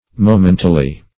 momentally - definition of momentally - synonyms, pronunciation, spelling from Free Dictionary Search Result for " momentally" : The Collaborative International Dictionary of English v.0.48: Momentally \Mo*men"tal*ly\, adv.